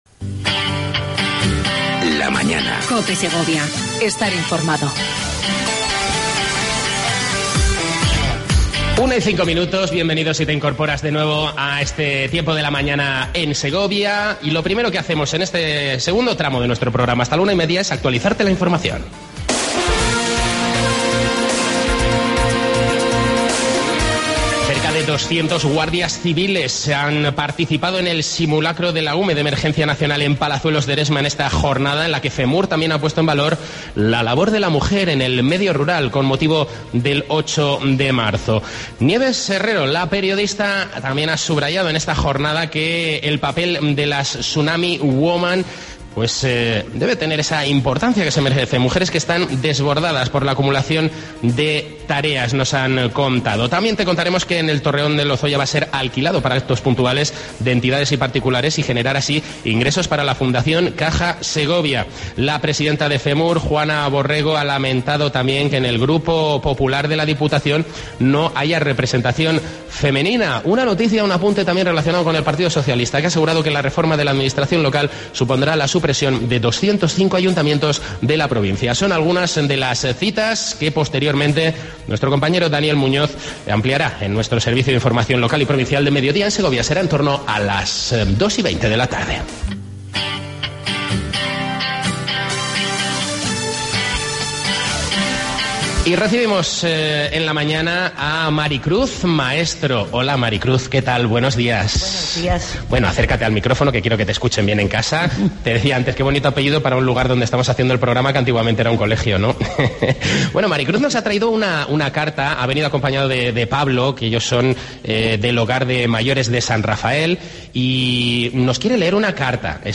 AUDIO: Edición Especial desde El Espinar con motivo de la fiesta de Los Gabarreros.